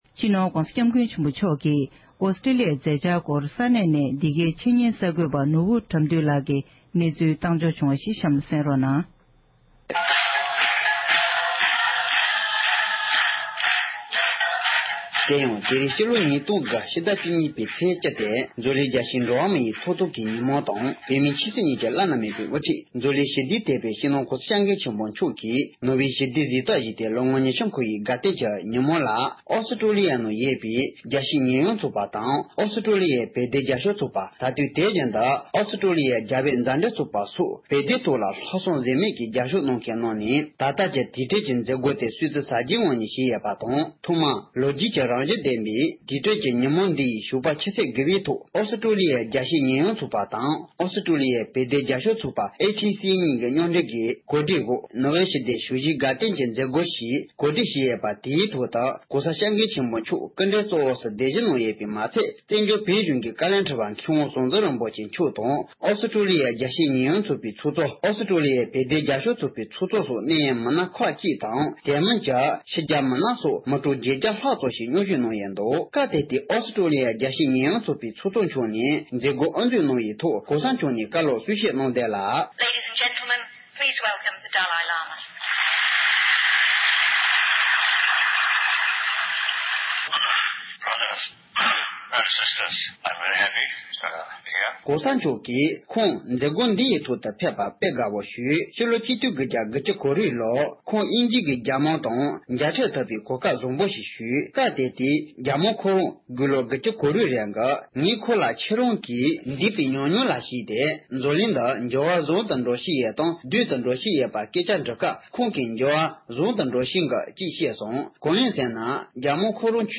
༄༅༎ཕྱི་ཟླ་བཅུ་གཉིས་པའི་ཚེས་བཅུ་རེས་གཟའ་ཕུར་བུའི་ཉིན་བོད་མིའི་ཆོས་སྲིད་གཉིས་ཀྱི་དབུ་ཁྲིད་སྤྱི་ནོར་༸གོང་ས་༸སྐྱབས་མགོན་ཆེན་པོ་མཆོག་ལ་ནོ་སྦེལ་ཞི་བདེའི་གཟེངས་འབུལ་བཞེས་མཛད་ནས་མི་ལོ་ཉི་ཤུ་ཧྲིལ་པོ་འཁོར་བར་བོད་མི་གནས་འཁོད་ཨིསྟྲེ་ལི་ཡ་དང་།བཞུགས་སྒར་རྡ་རམ་ས་ལ།ལྡི་ལི་སོགས་ས་གནས་གང་སར་སྲུང་བརྩི་ཞུས་ཡོད་པའི་སྐོར་ཨེ་ཤེ་ཡ་རང་དབང་རླུང་འཕྲིན་ཁང་གི་གསར་འགོད་པ་ཁག་ནས་གནས་ཚུལ་བཏང་འབྱོར་བྱུང་ཁག་ཅིག་གསན་རོགས༎